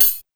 FX140CYMB1-L.wav